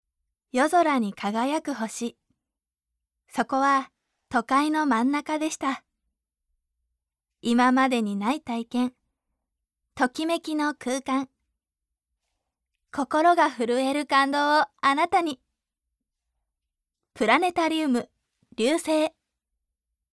ボイスサンプル
ナレーション①(プラネタリウム)